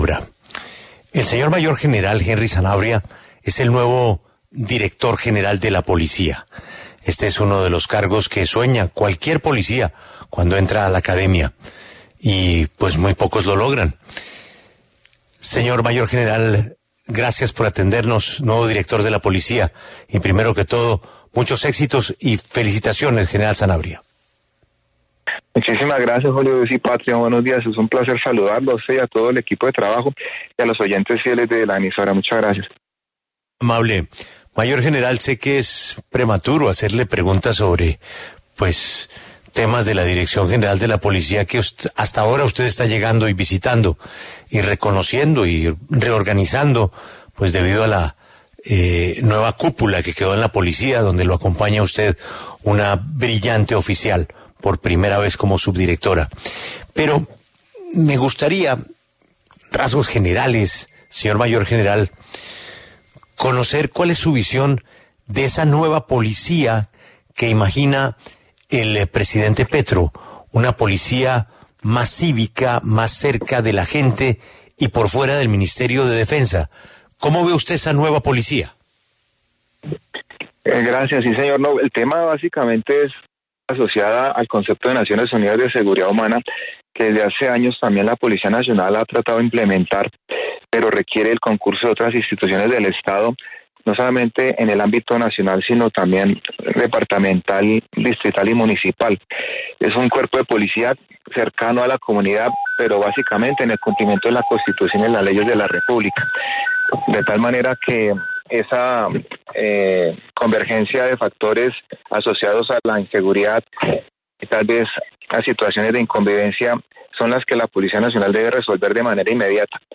En diálogo con La W, el mayor general Henry Armando Sanabria Cely, nuevo director de la Policía Nacional, habló sobre los retos que enfrentará a la cabeza de la institución.